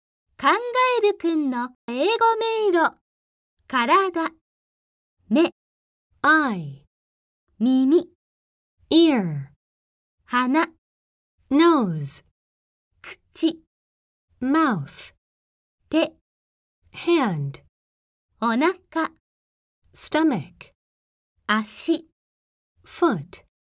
ネイティブスピーカーによる発音でお聞きいただけます。